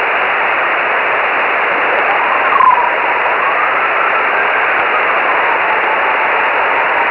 Vous reconnaîtrez le Doppler caractéristique en forme de plume (au début, la fréquence de l'écho est supérieure a celle de son signal terrestre)
Le rapport signal/bruit atteint 12 dB par moments. On notera l'effet Doppler, dérive de fréquence caractéristique due à la cible en mouvement rapide"